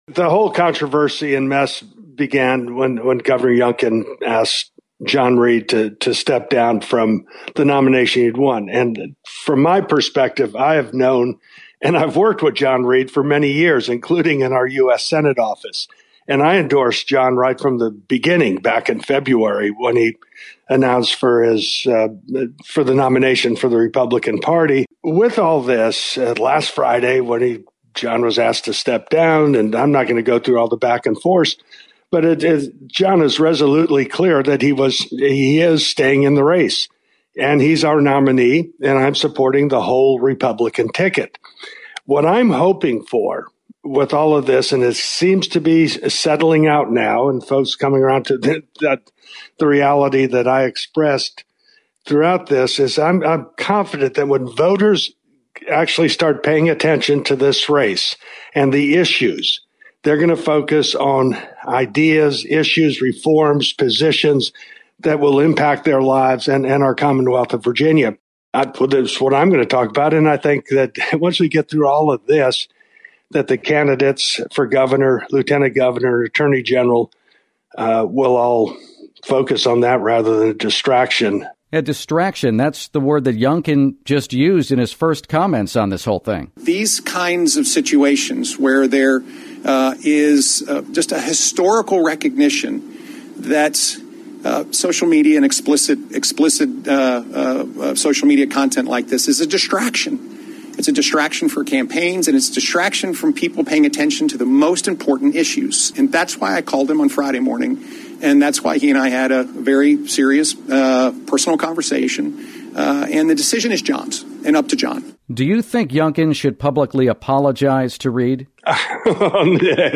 Former Gov. George Allen talks about the controversy